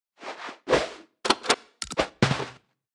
Media:Sfx_Anim_Ultra_Shelly.wav 动作音效 anim 在广场点击初级、经典、高手和顶尖形态或者查看其技能时触发动作的音效